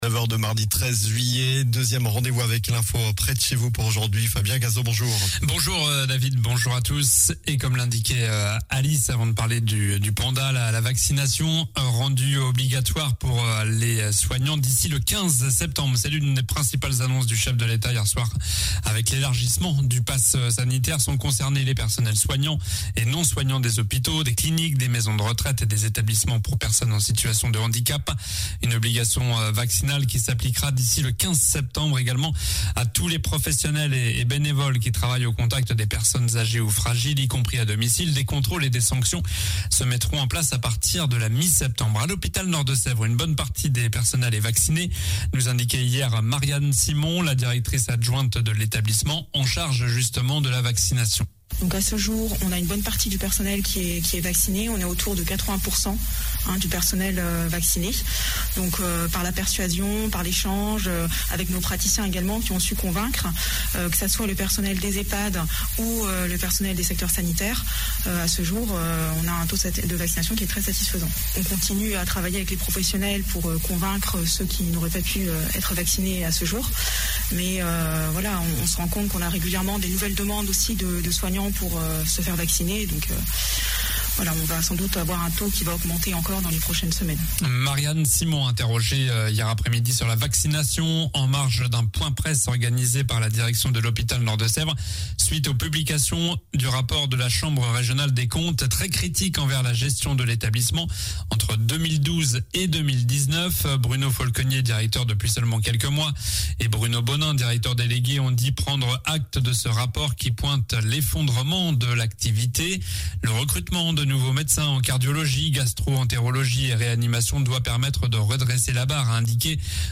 Journal du mardi 13 juillet (matin)